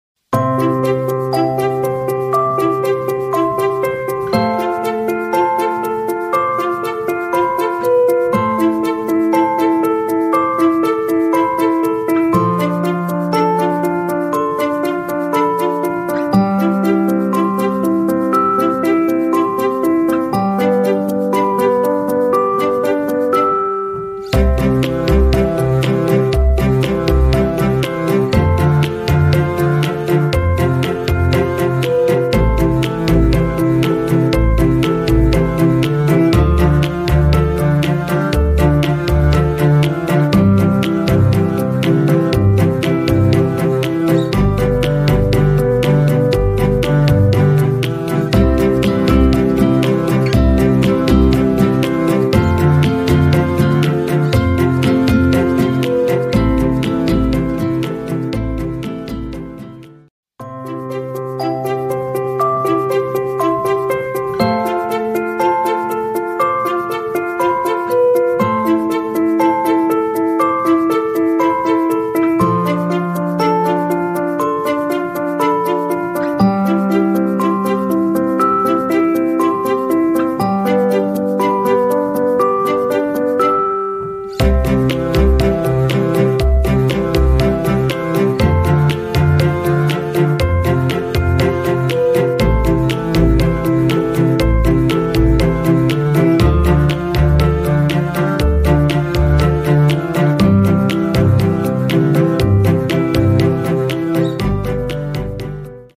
Optometry Refractor Computerized Eye Test sound effects free download
Optometry Refractor Computerized Eye Test Auto Vision Tester Optical Digital Automatic Phoropter